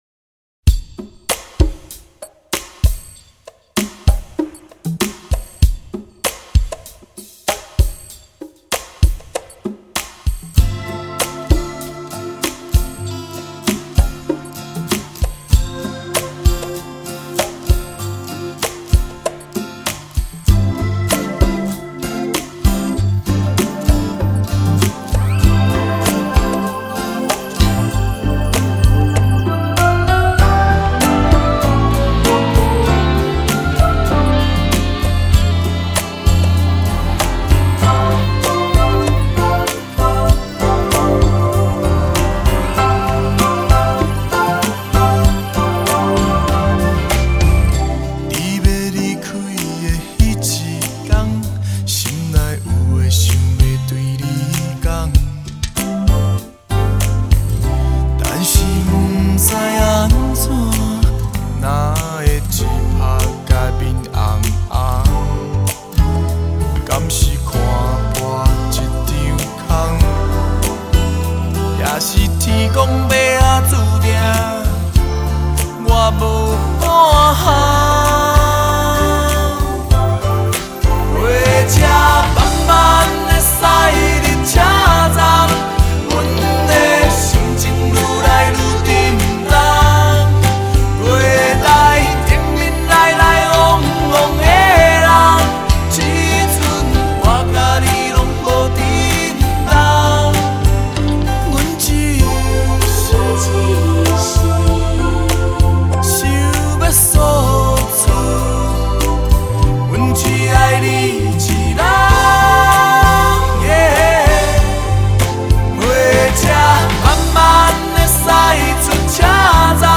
间奏部份还吹了一段口琴演奏，为这首离愁淡淡的歌曲，增添了戏剧感